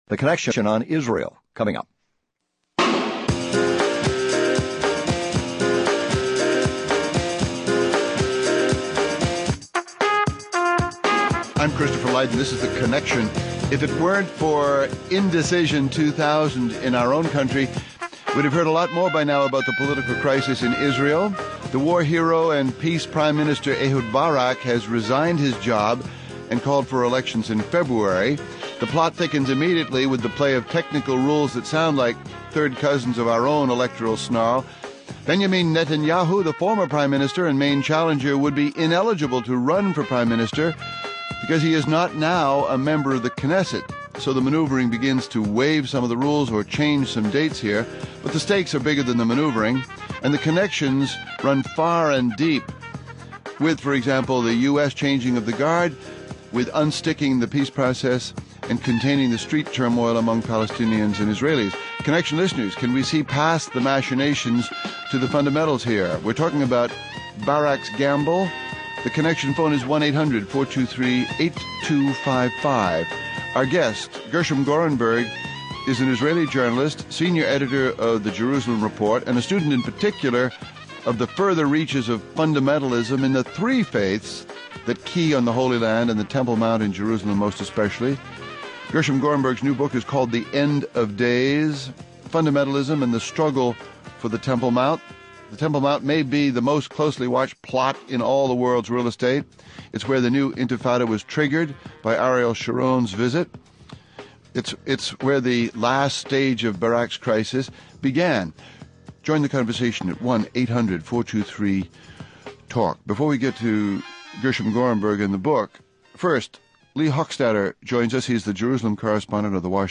Paul Krugman, columnist for The New York Times and Professor of Economics at Princeton University